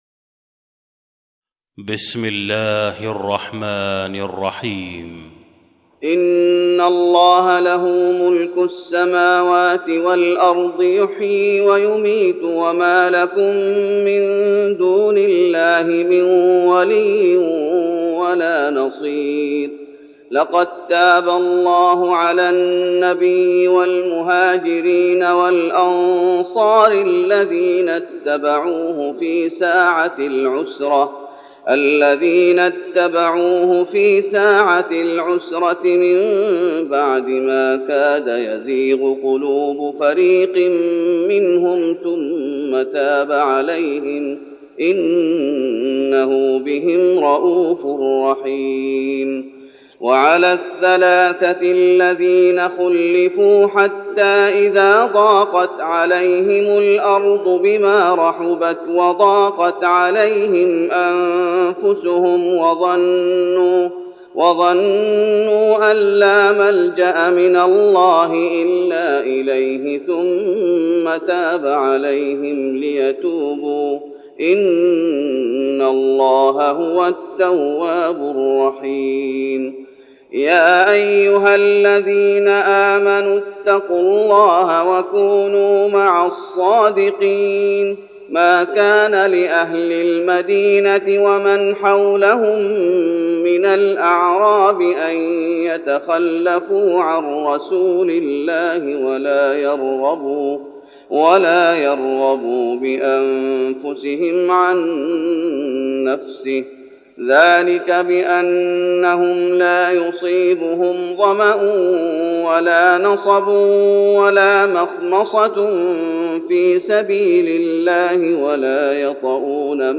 تهجد رمضان 1412هـ من سورة التوبة (116-129) Tahajjud Ramadan 1412H from Surah At-Tawba > تراويح الشيخ محمد أيوب بالنبوي 1412 🕌 > التراويح - تلاوات الحرمين